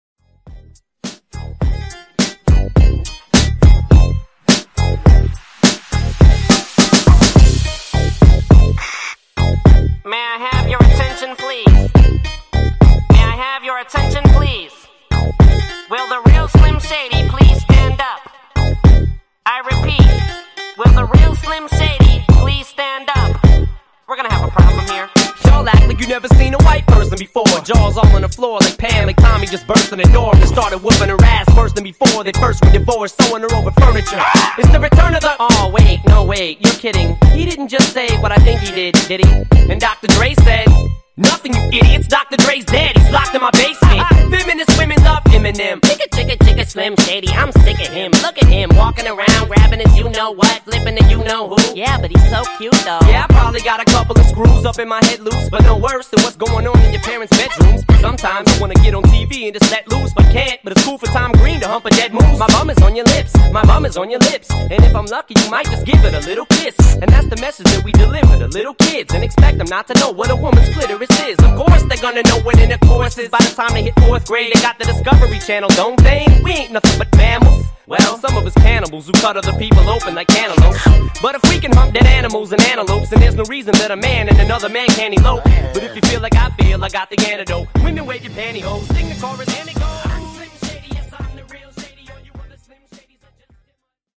Genre: RE-DRUM
Clean BPM: 94 Time